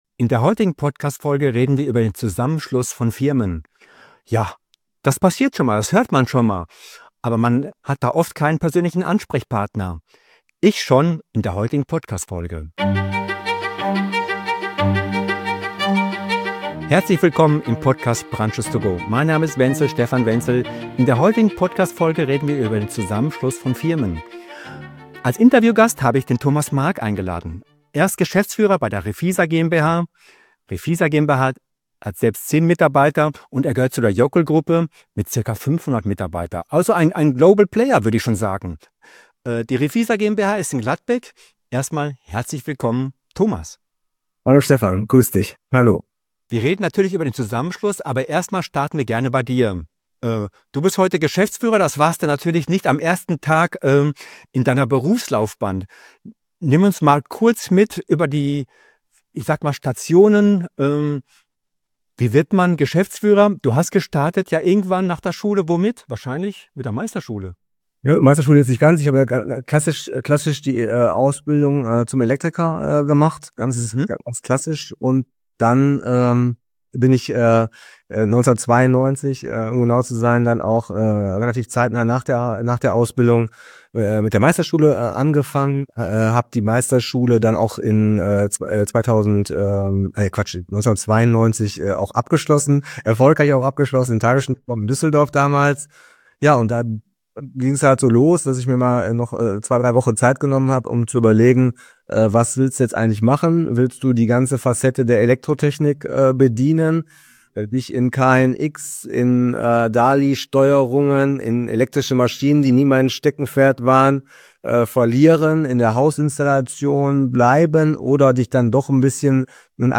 #071 Der Zusammenschluss von Firmen, ein Praxis-Beispiel - Interview